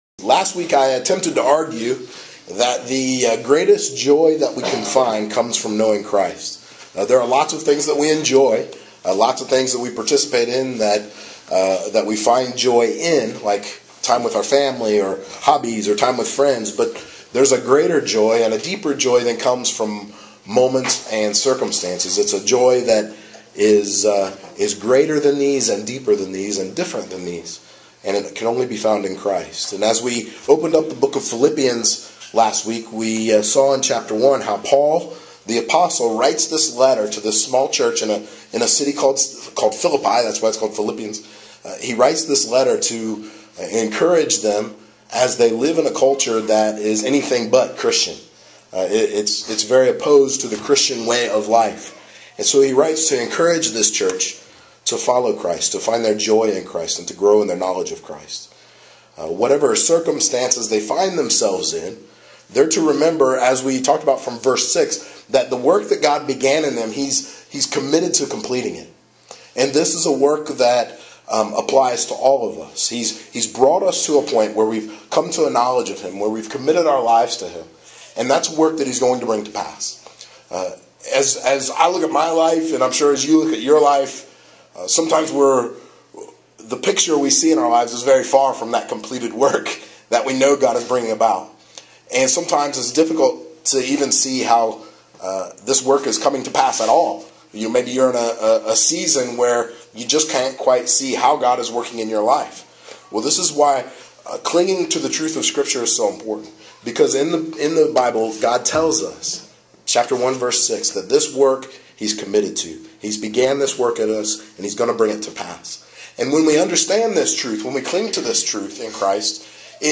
The second of four sermons through the New Testament Book of Philippians from the Spring of 2014